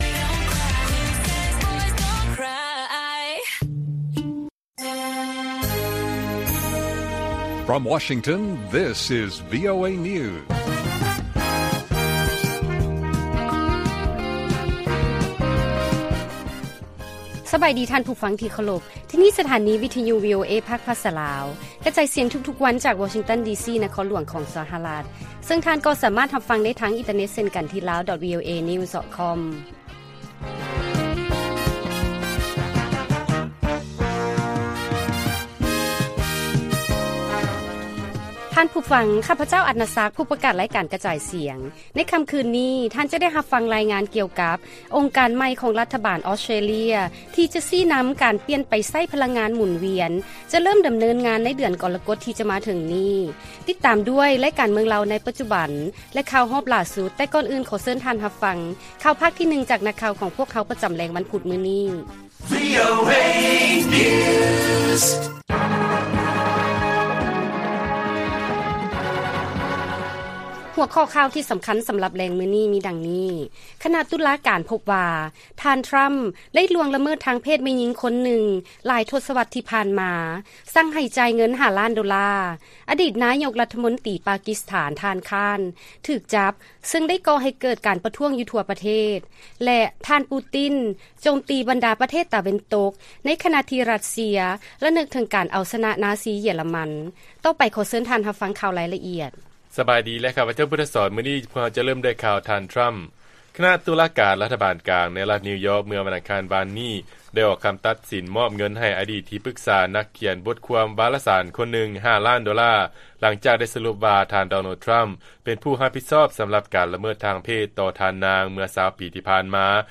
ວີໂອເອພາກພາສາລາວ ກະຈາຍສຽງທຸກໆວັນ, ຫົວຂ່າວສໍາຄັນໃນມື້ນີ້ມີ: 1. ຄະນະຕຸລາການພົບວ່າ ທ່ານ ທຣຳ ໄດ້ລ່ວງລະເມີດທາງເພດແມ່ຍິງຄົນນຶ່ງ, 2. ອະດີດນາຍົກລັດຖະມົນຕີປາກິສຖານ ທ່ານຄານ ຖືກຈັບ ຊຶ່ງໄດ້ກໍ່ໃຫ້ເກີດການປະທ້ວງຢູ່ທົ່ວປະເທດ, ແລະ 3. ປູຕິນ ໂຈມຕີບັນດາປະເທດຕາເວັນຕົກ ໃນຂະນະທີ່ ຣັດເຊຍ ລະນຶກເຖິງການເອົາຊະນະ ນາຊີ.